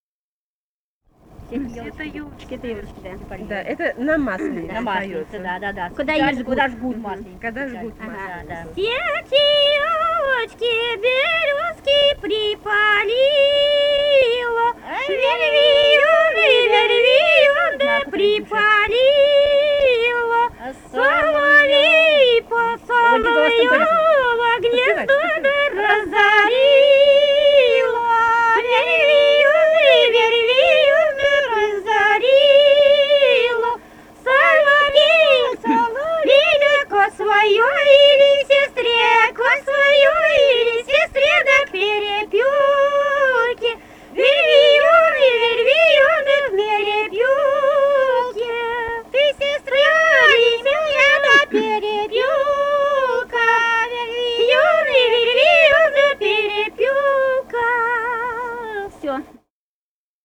Этномузыкологические исследования и полевые материалы
Костромская область, с. Дымница Островского района, 1964 г. И0789-21